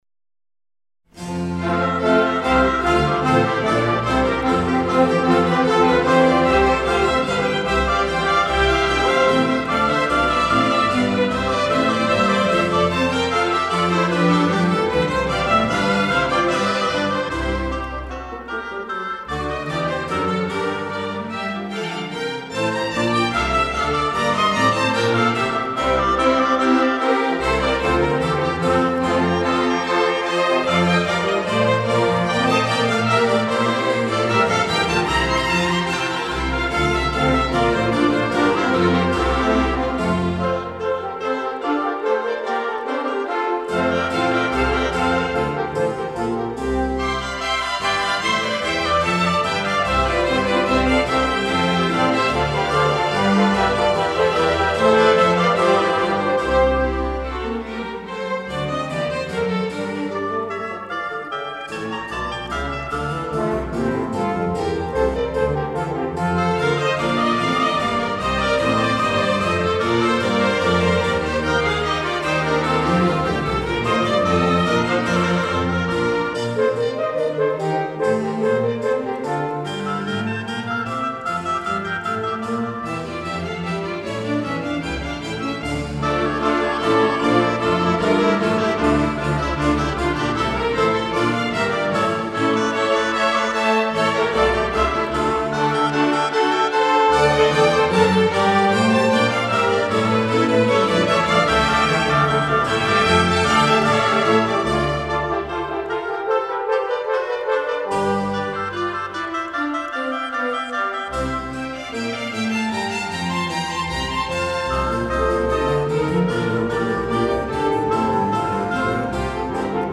音樂類型：古典音樂
這套作品是以巴洛克時代大協奏曲的風格寫成，也就是不以獨奏家對抗樂團的型式，而是以獨奏家團對抗樂團的行式譜成。